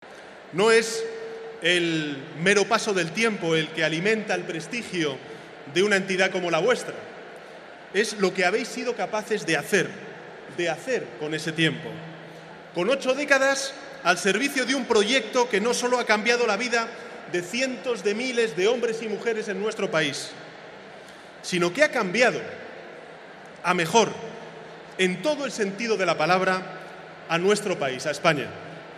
matizaba Sánchez formato MP3 audio(0,55 MB) en una emotiva intervención ante un auditorio de casi 5.600 miembros del Grupo Social ONCE en la Comunidad madrileña.